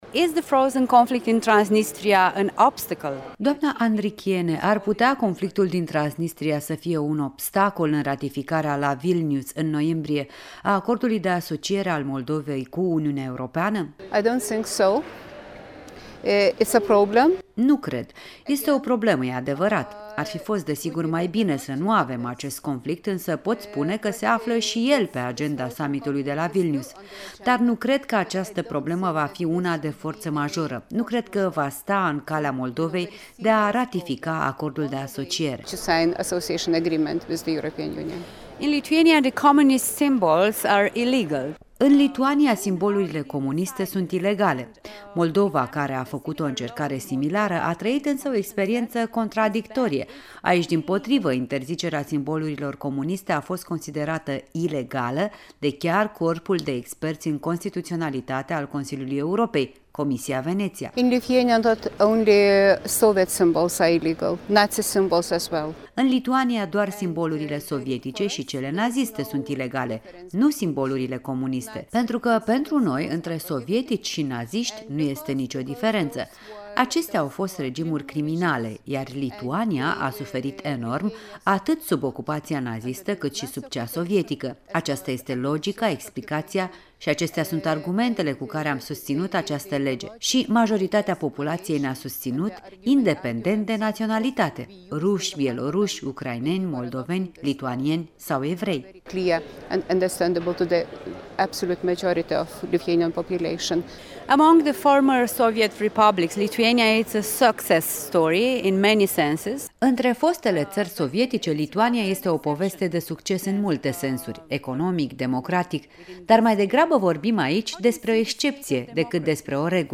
În dialog la Strasburg cu Laima Andrikiene, membră a Delegației de cooperare cu Moldova a PE